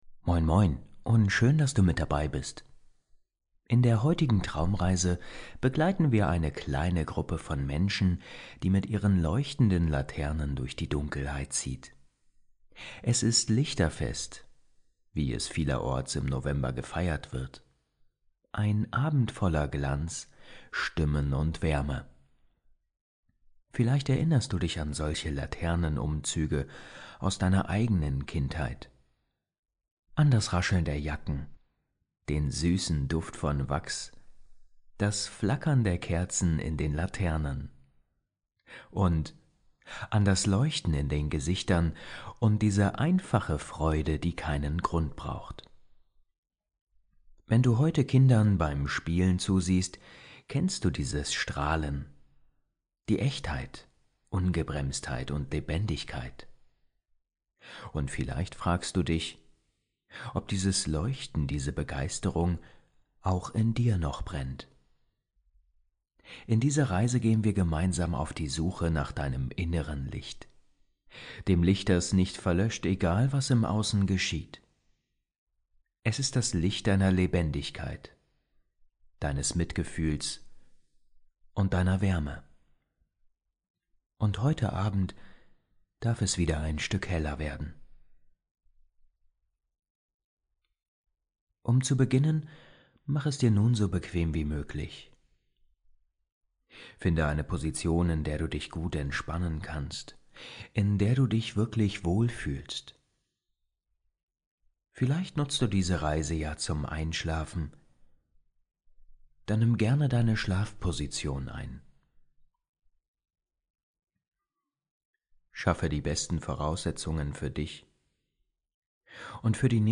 Hörst du diese Traumreise zum Einschlafen?